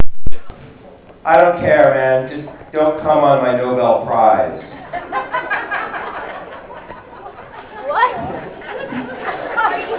(Bonus: I closed my set at Long Island Club with a joke I haven't told in a while.
You can hear a clip of me telling the final punchline, without context, and that skinny drunk woman in the front row reacting to it, right